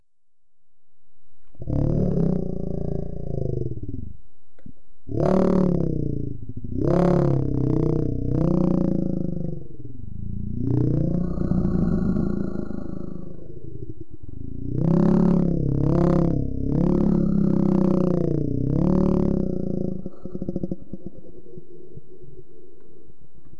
吓人的怪物声音
这个片段是用一个语音剪辑和一些音频编辑制作的。
Tag: 鬼魅般令人毛骨悚然 闹鬼 发牢骚 轰鸣声 怪物 可怕的